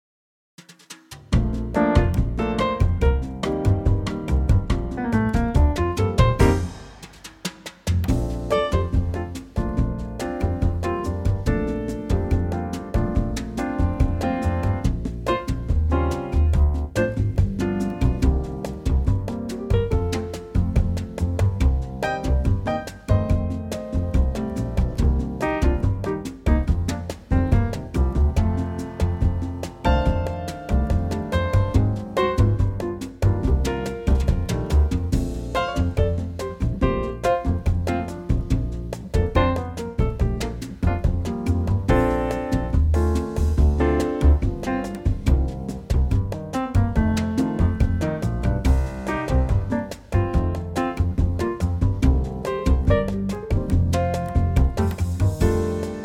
key - Bb - vocal range - A to D
Trio arrangement
same tempo, form and a few similar lines, but in a male key.